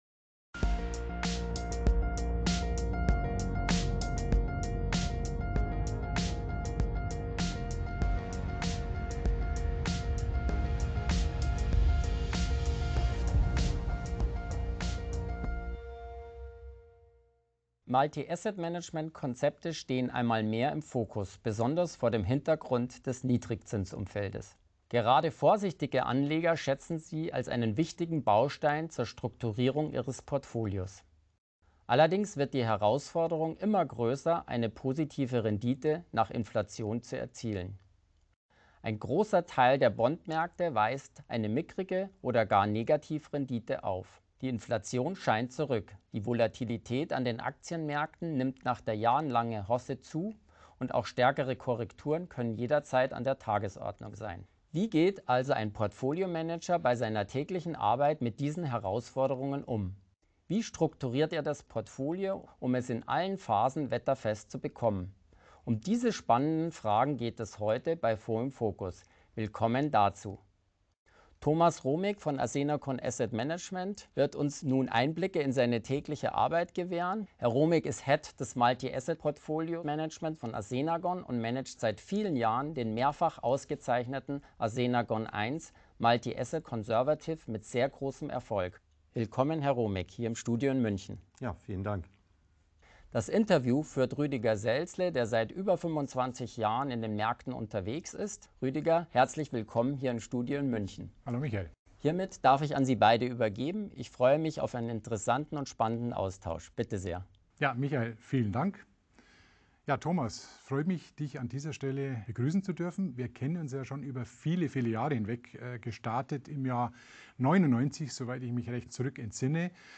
Audio zum Webinar am 30.6.2021 mit Assenagon